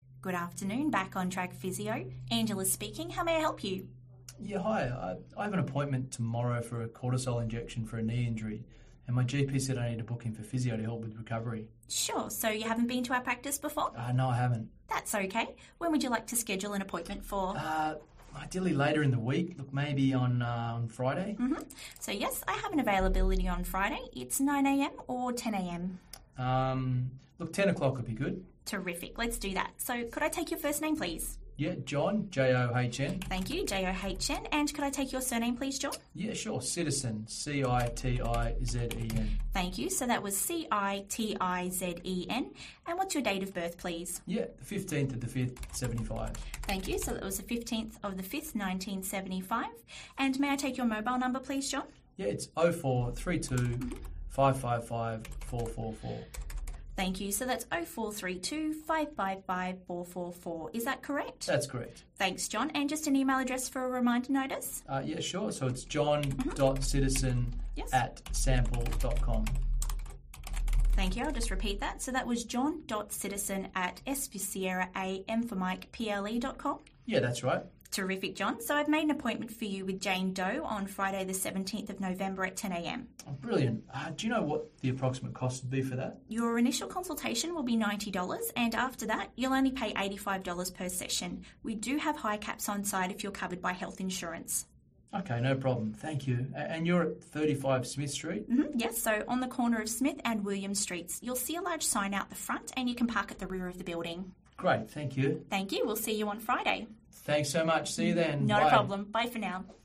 medical-healthcare-virtual-receptionist-sample-call.mp3